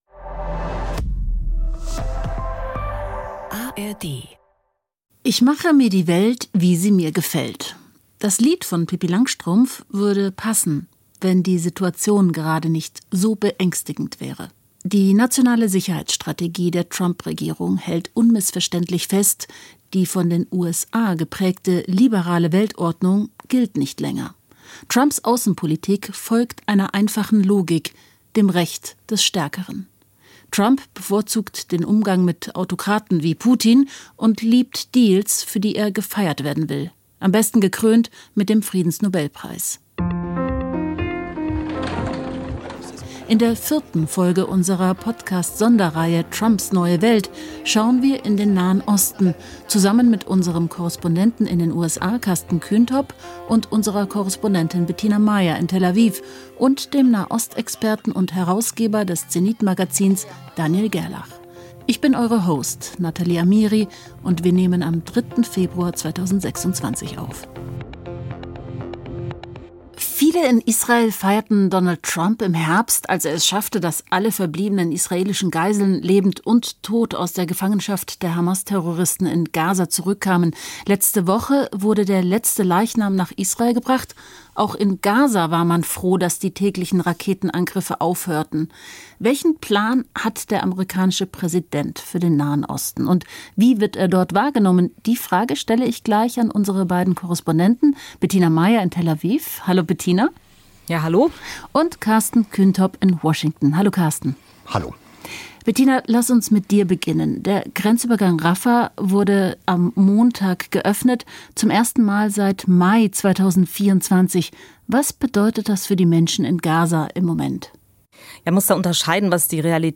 Moderation: Natalie Amiri